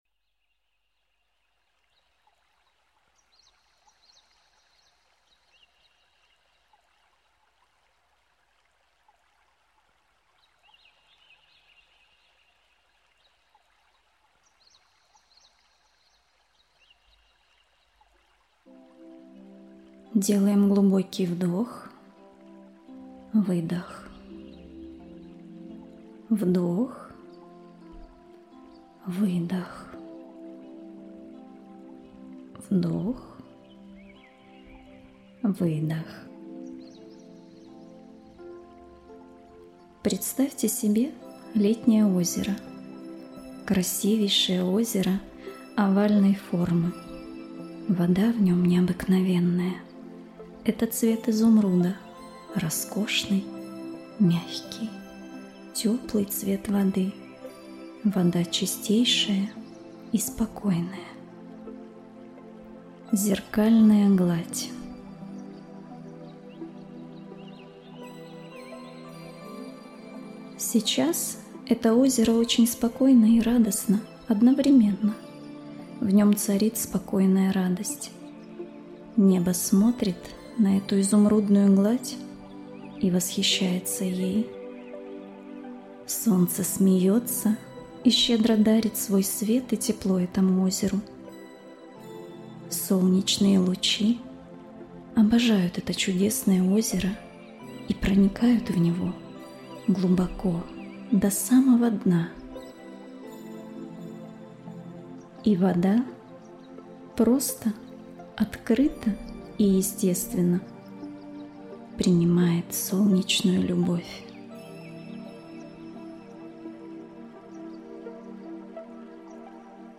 Медитация «Озеро любви»